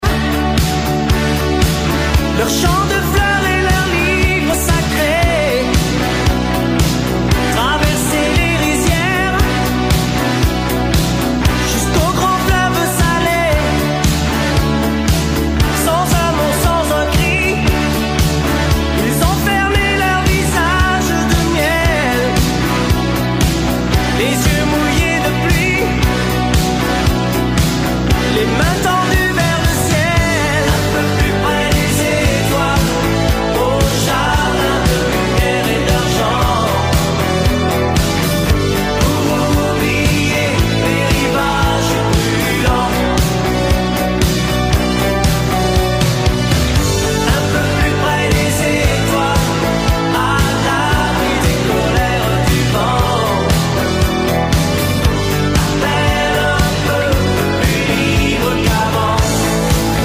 #80smusic